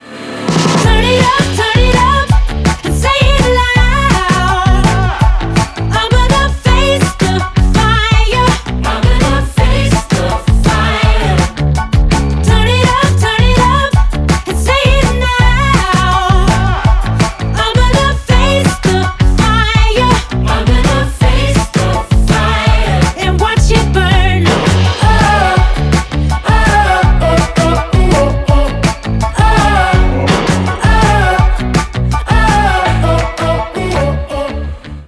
Веселая песенка
с необычным исполнением